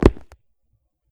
footstep6.wav